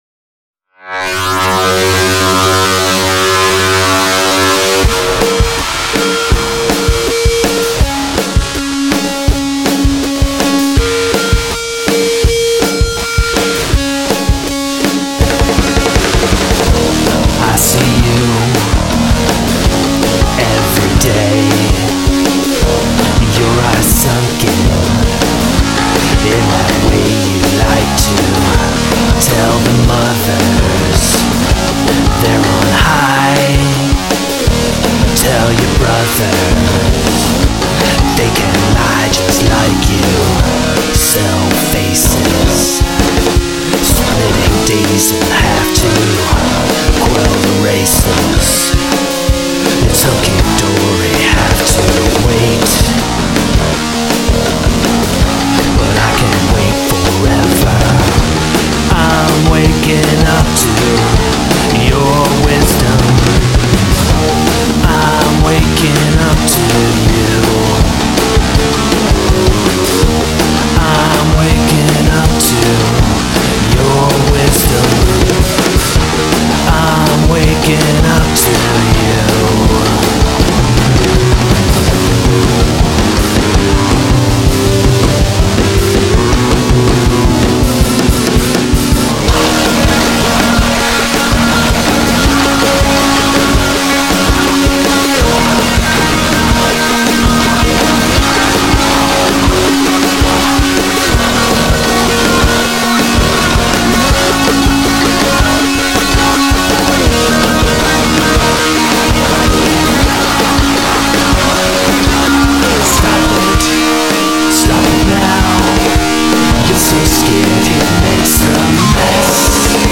Punk
Rock & Roll
Indy